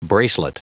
Prononciation du mot bracelet en anglais (fichier audio)
Prononciation du mot : bracelet